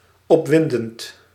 Ääntäminen
IPA: /pa.sjɔ.nɑ̃/